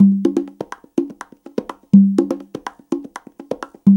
CONGA BEAT41.wav